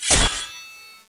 Attack.wav